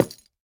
Minecraft Version Minecraft Version 1.21.5 Latest Release | Latest Snapshot 1.21.5 / assets / minecraft / sounds / block / cherry_wood_hanging_sign / break4.ogg Compare With Compare With Latest Release | Latest Snapshot
break4.ogg